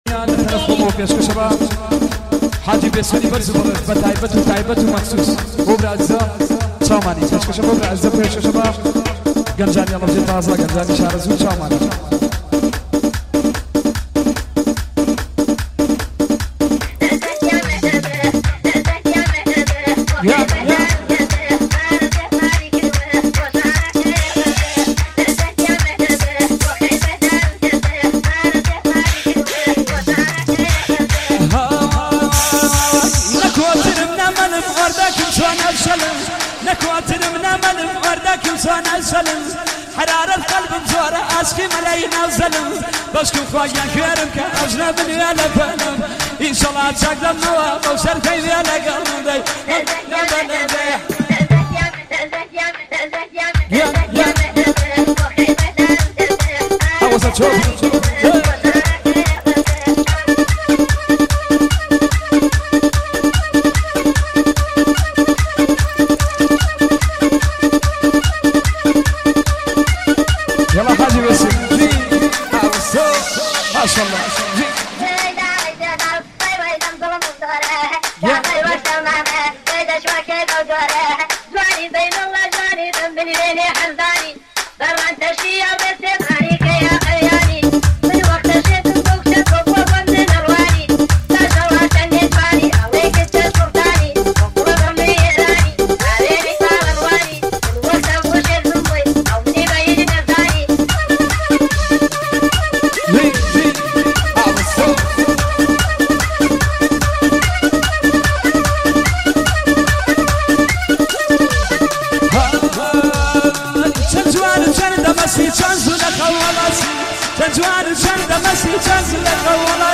موزیک شاد کردی ویژه رقص و هلپرکی
موزیک کوردی شاد ویژه هلپرکه کوردی